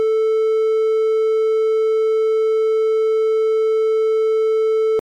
Triangle